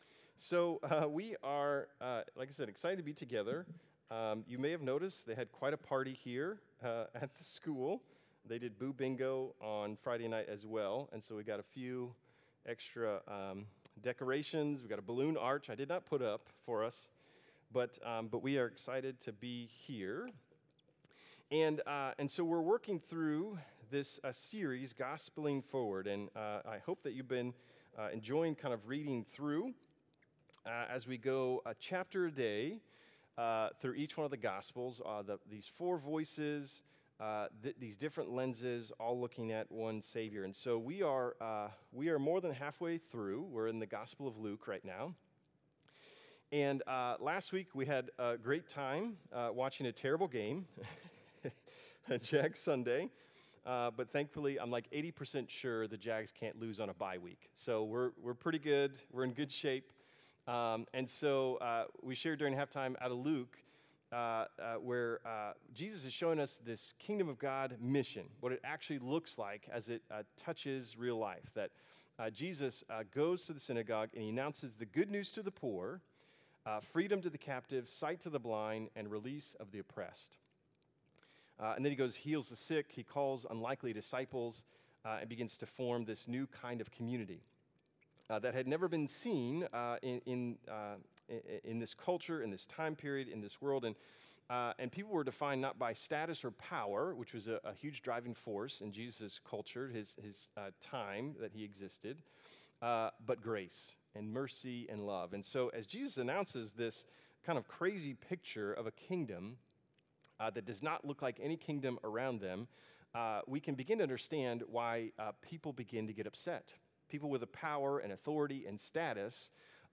Sermons | Bridge City Church